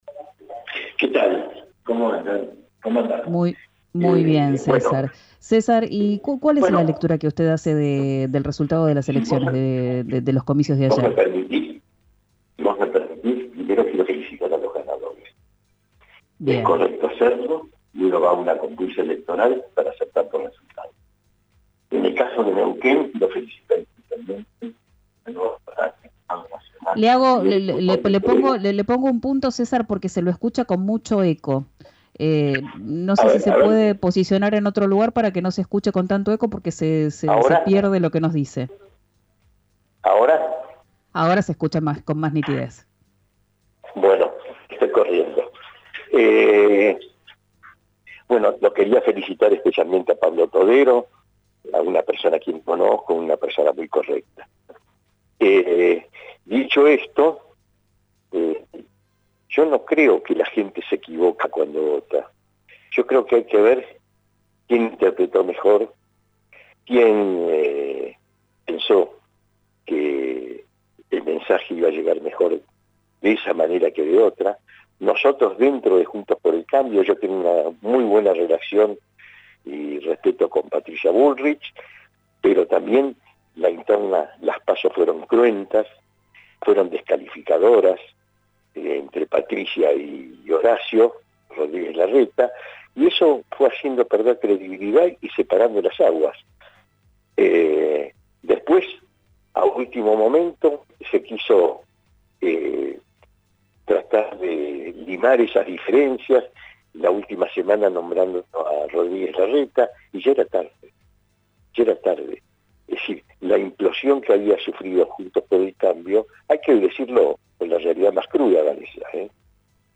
En diálogo con RIO NEGRO RADIO, el diputado de Neuquén y dirigente de la UCR, César Gass, acusó a Mauricio Macri de «confundir al electorado» y garantizó «A Milei no lo votaría».